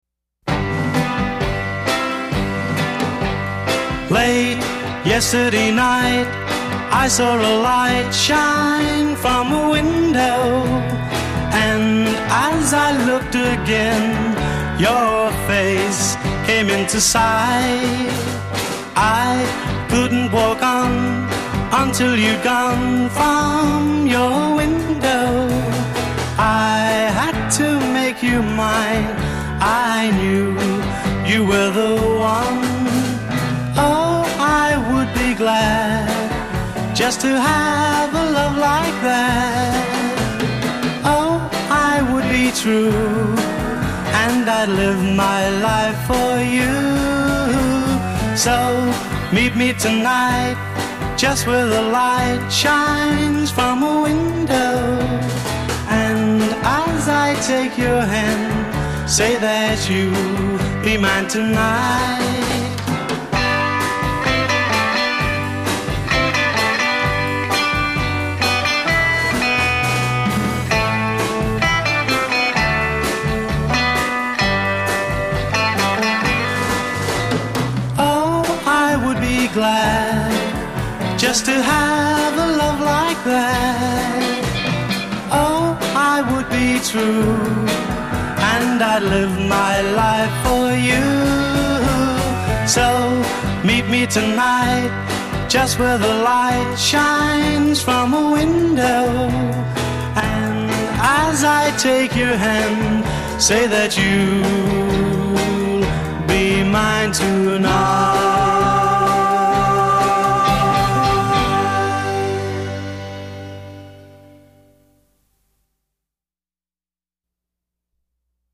bass guitar
drums
intro 0:00 2 instrumental vamp (establish key and meter)
A verse : 8 guitar-piano solo